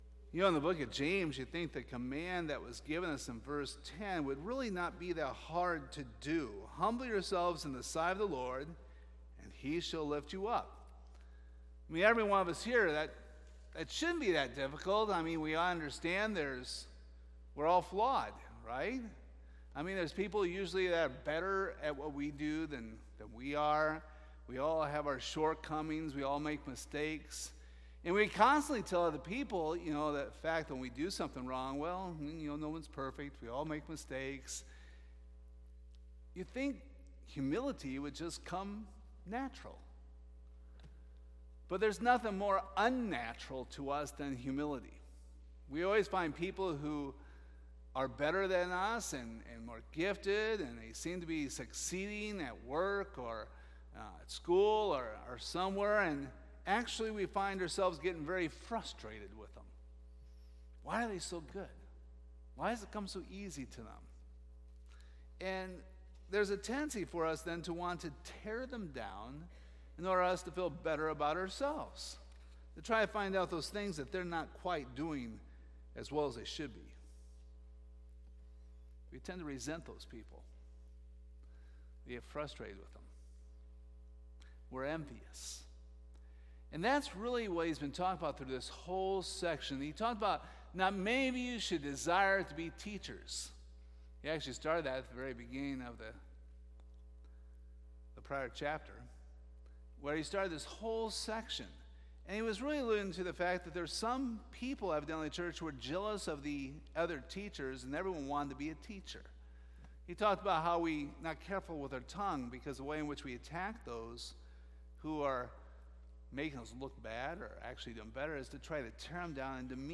This sermon was cut shorter due to having communion afterward*
Service Type: Sunday Evening